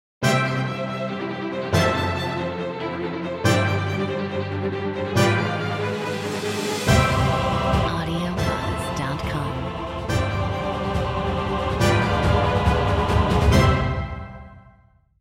Royalty Free Patriotic Music
Metronome 140